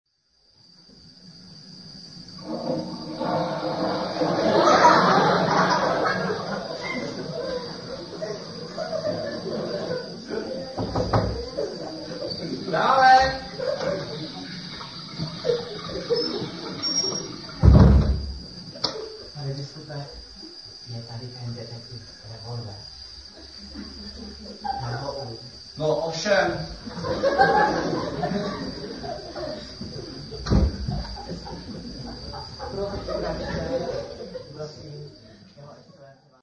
Vystoupení se konalo 29. června 1999.